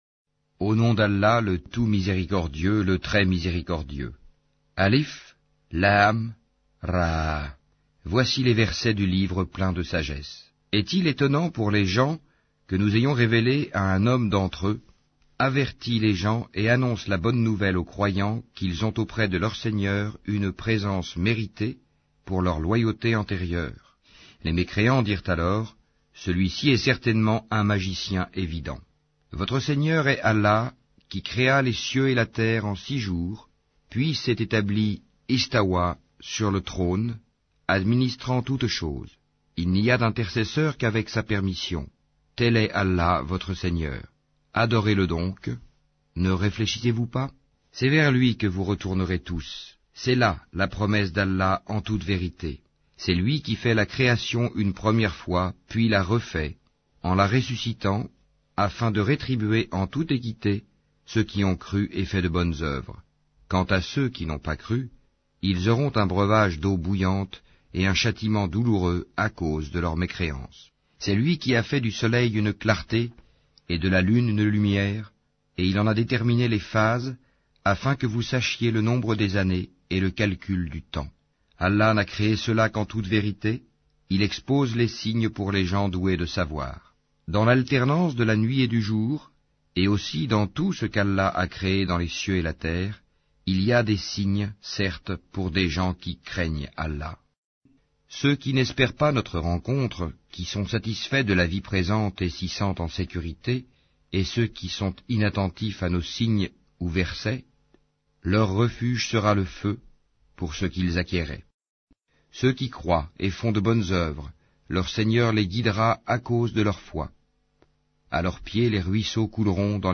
Yunus Lecture audio